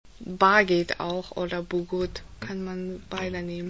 Originalton Lehrerin